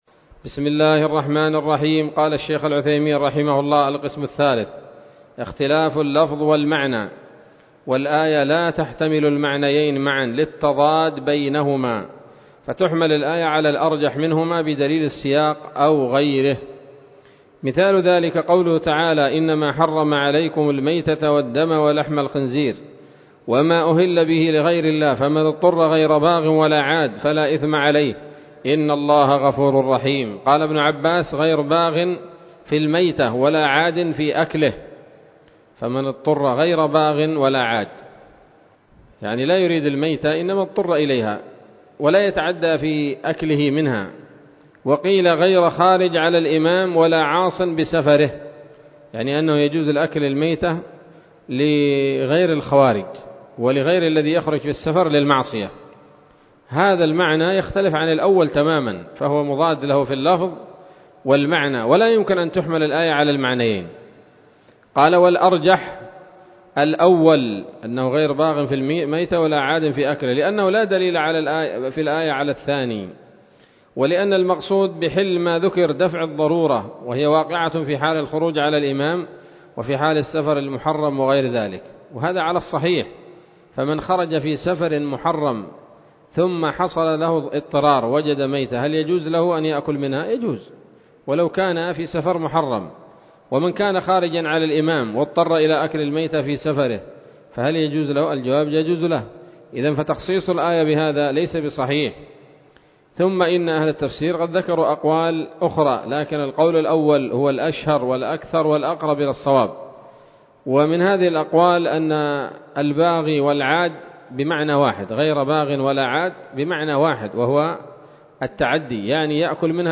الدرس الثاني والعشرون من أصول في التفسير للعلامة العثيمين رحمه الله تعالى 1446 هـ